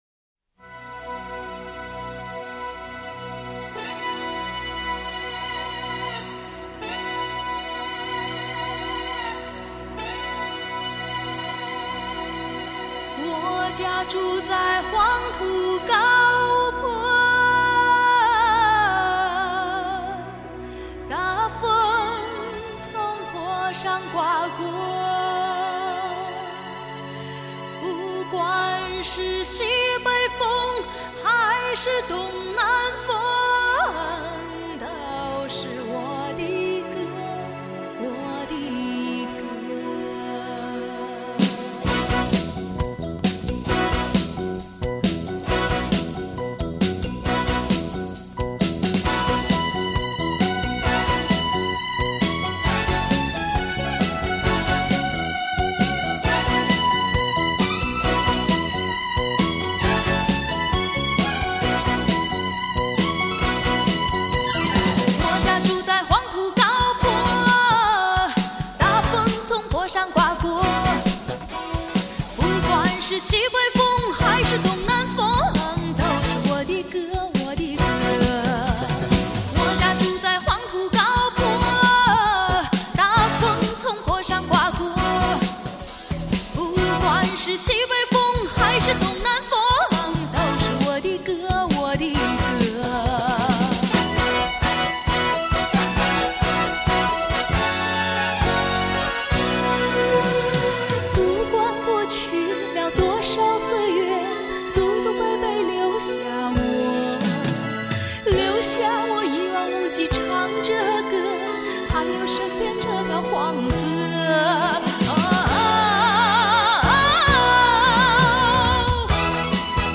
本碟歌曲之原录音带由20bit数码系统重新编制
音场透明度及层次感大大增加
频应明显扩张 动态更具震撼